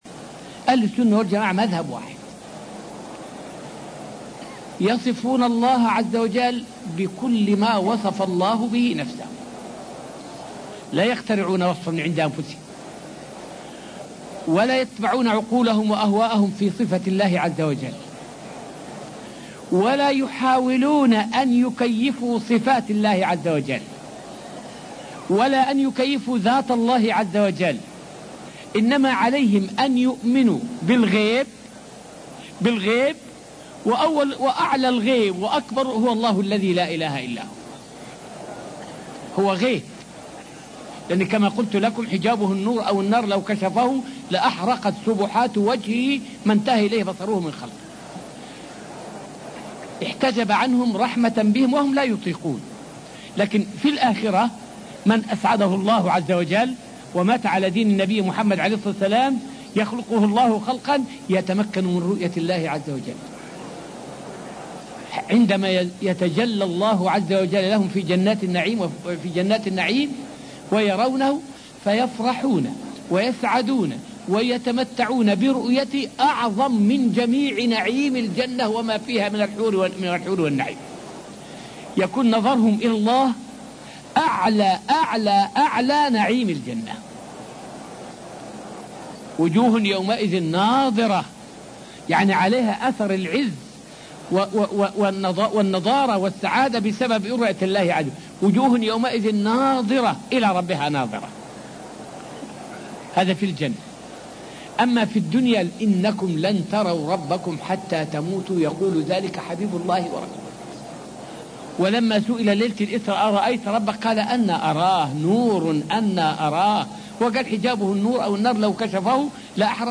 فائدة من الدرس الخامس والعشرون من دروس تفسير سورة البقرة والتي ألقيت في المسجد النبوي الشريف حول واجب المسلم عند تفرق المسلمين.